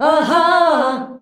AHAAH F.wav